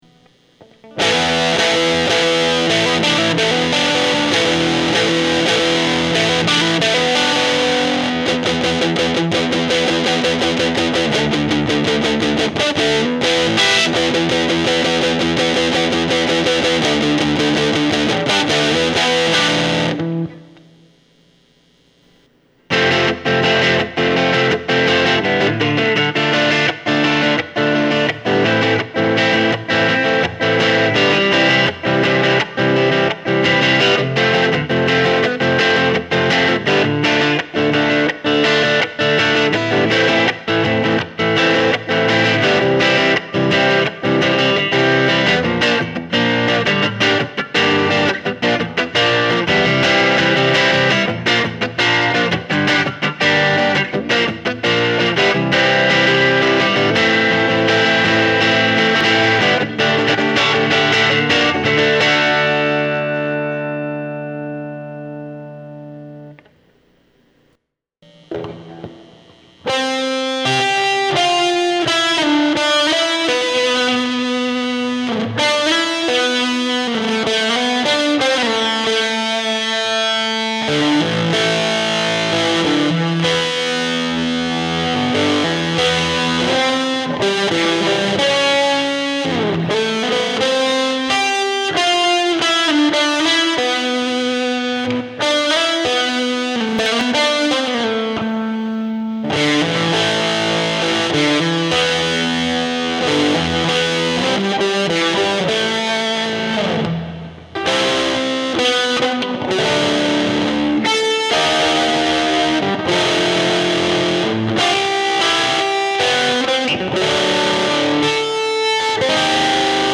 The preamp is a slightly modified version of the London Power preamp, which uses two 12AX7 tubes to achieve a clean channel and a high gain channel.
Audio Sample 1 [3:48, 137 kbps, 3.9 Mb]: I recorded this using a Radio Shack microphone right in front of an 12 inch Electro Harmonix 12VR8 speaker in an open back cabinet. The only post processing I did was to add reverb. I recorded it using Audacity on a Linux netbook, and there are audio glitches in the recording.